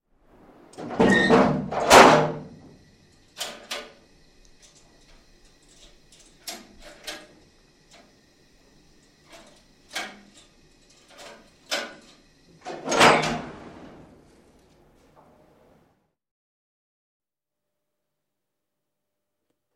Звуки сейфа
Звук открывающейся двери банковского хранилища